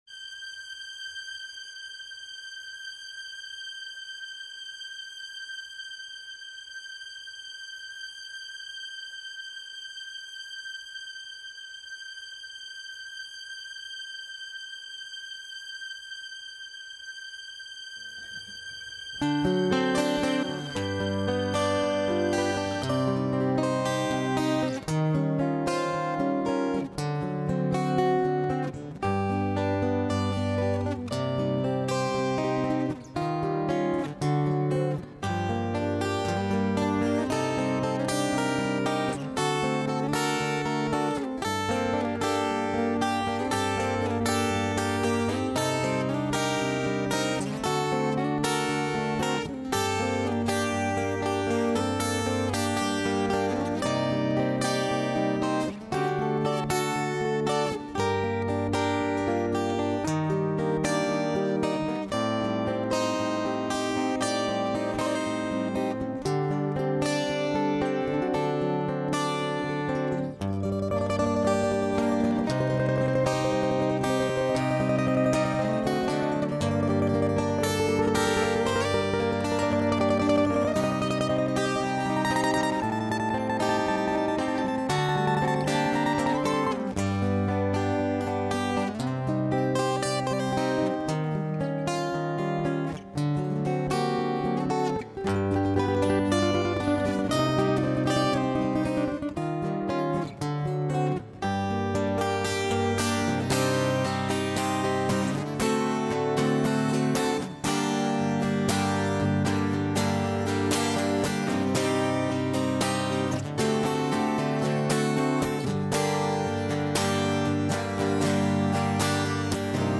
минусовка версия 14529